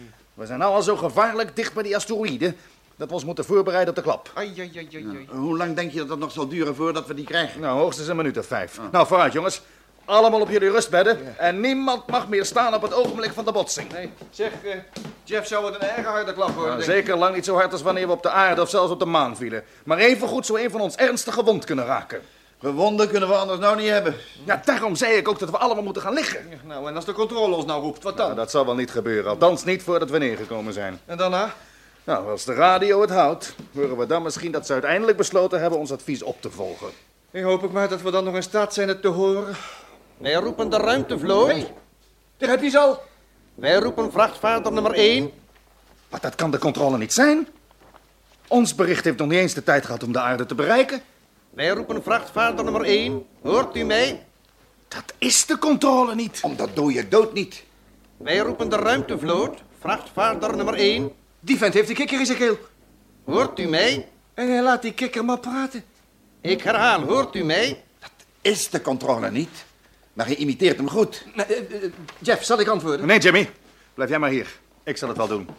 Natuurlijk klinkt de stem van de controle anders, als die wordt ingevuld door een Martiaan.